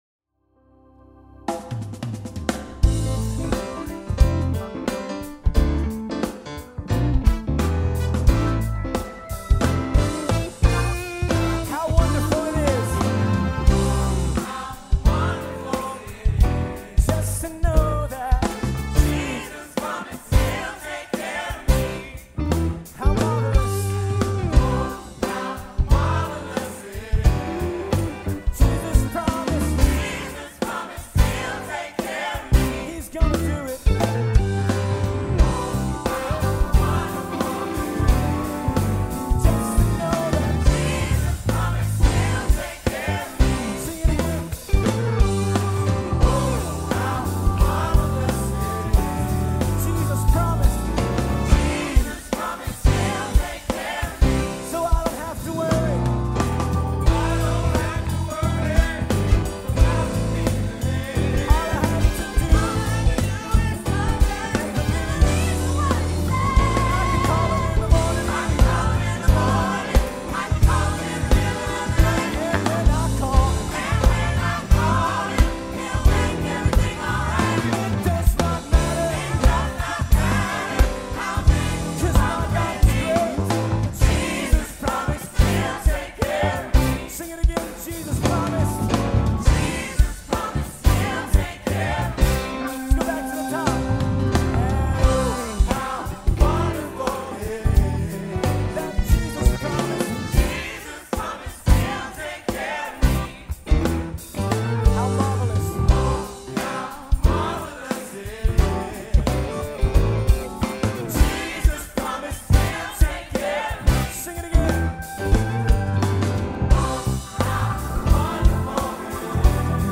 Mp3:or låtar: